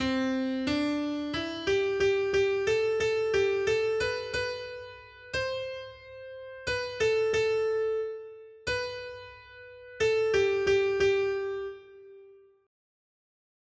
Noty Štítky, zpěvníky ol471.pdf responsoriální žalm Žaltář (Olejník) 471 Skrýt akordy R: Chvalte Hospodina, protože je dobrý. 1.